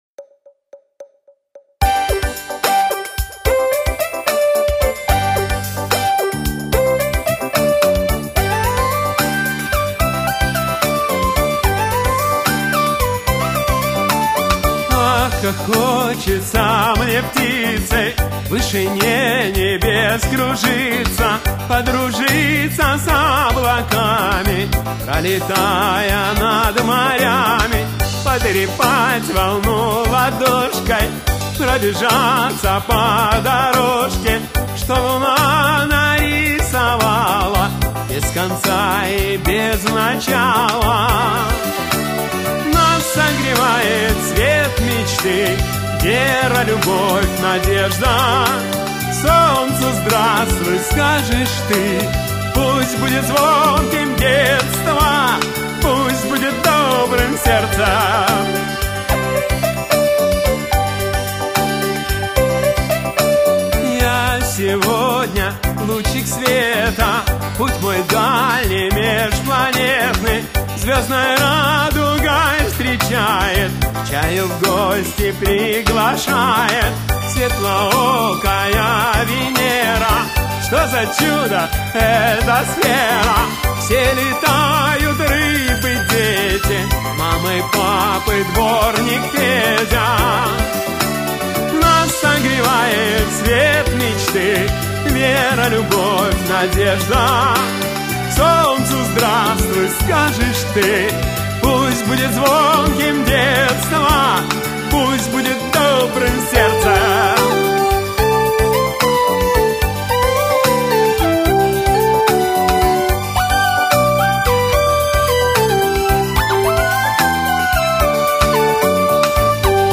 Детская музыка